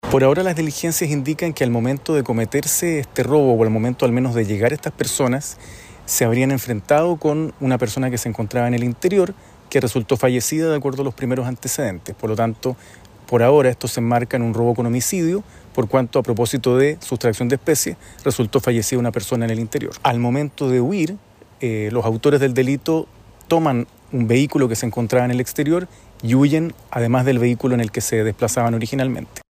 El fiscal Víctor Núñez, de la Fiscalía Metropolitana Sur, indicó que los asaltantes se dieron a la fuga en el auto que habían sustraído y también con el vehículo que llegaron al lugar.